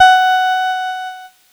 Cheese Note 12-F#3.wav